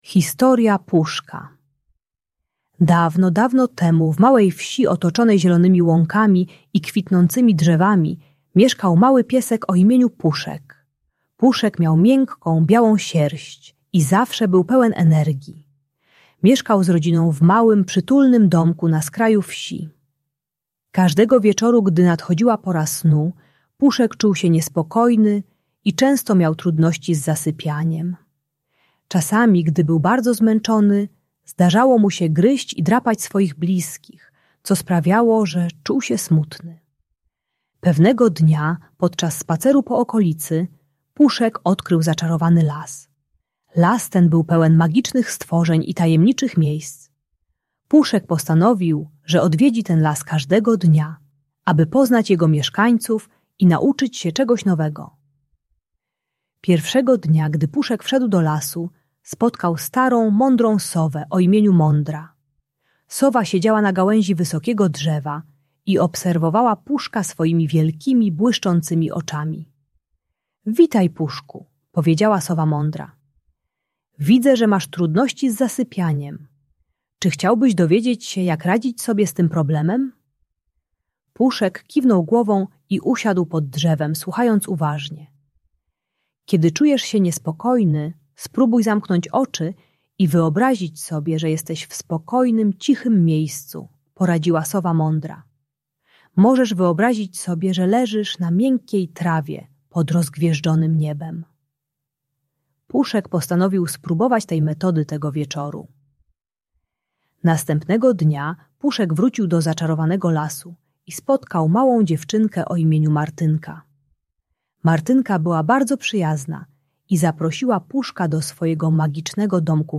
Historia Puszka - Magiczna Opowieść - Agresja do rodziców | Audiobajka